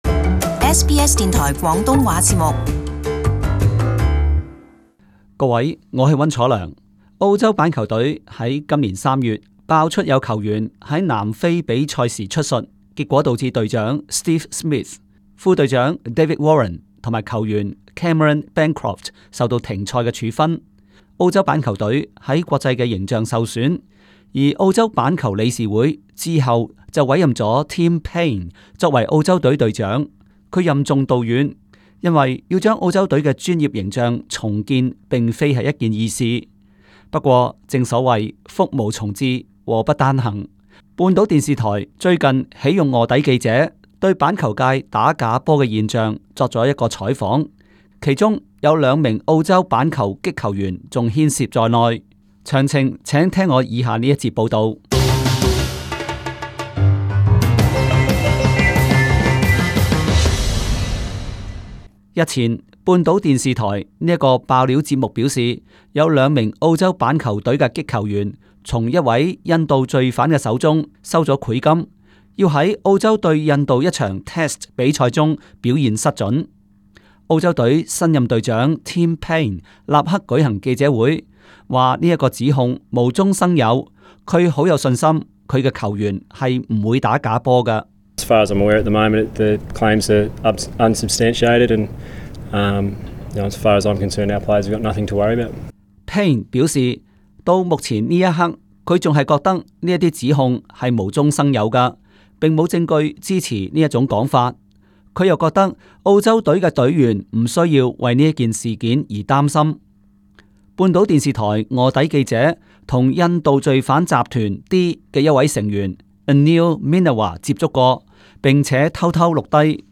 【時事報導】澳洲板球理事會否認有球員打假波